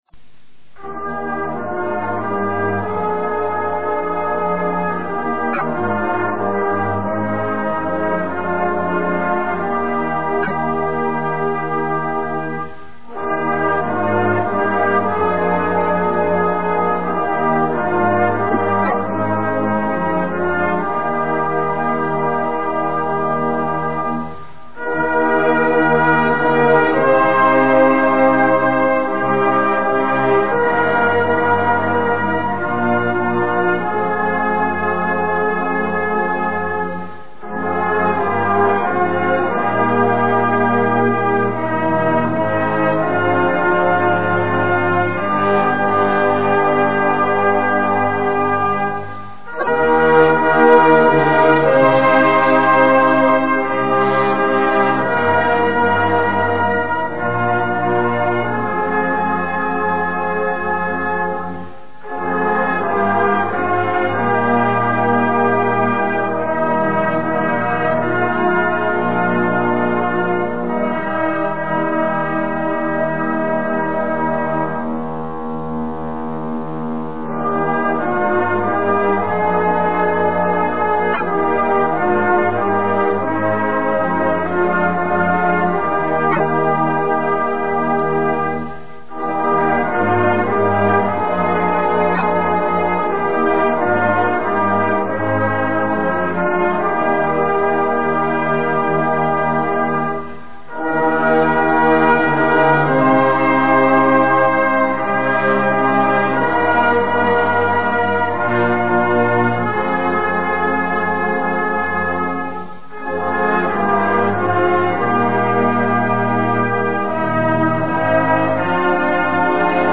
Key: E♭
Tempo: 96